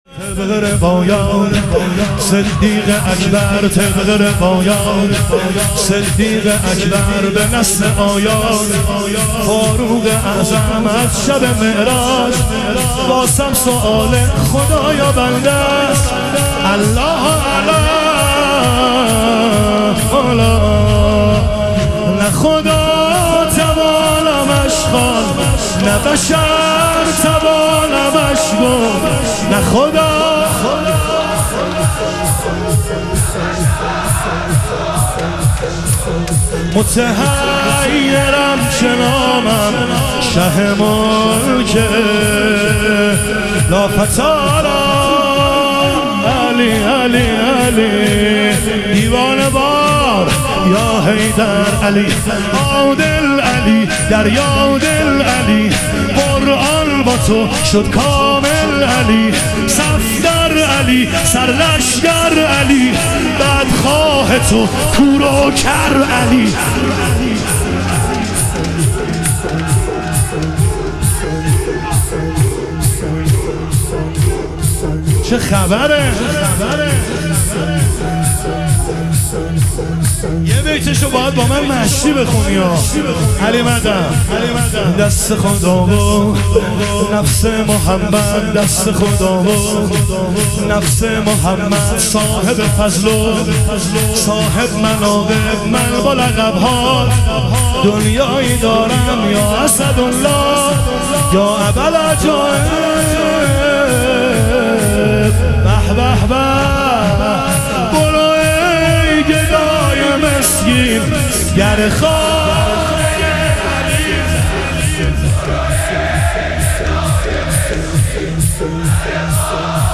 شهادت حضرت خدیجه علیها سلام - شور